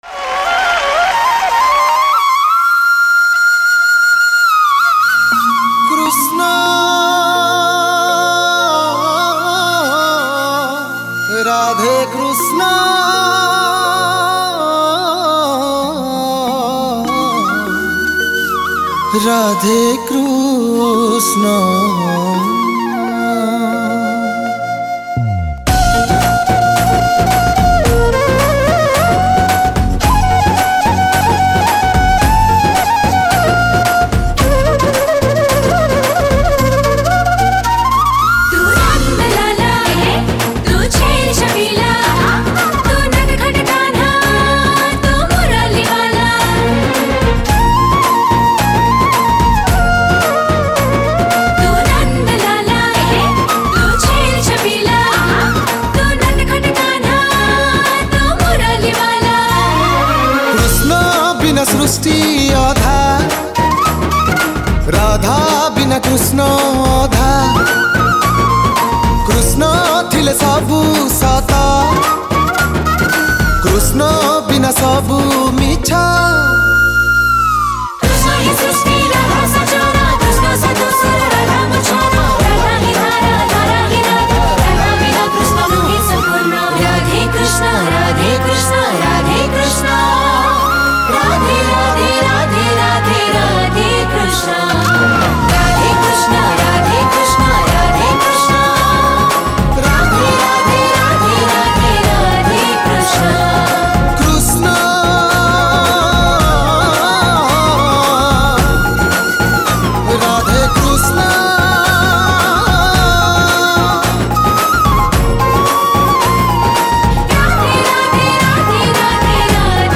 Category: New Odia Bhakti Songs 2022